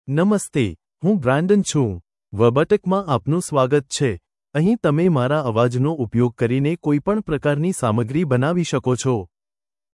MaleGujarati (India)
Brandon is a male AI voice for Gujarati (India).
Voice sample
Male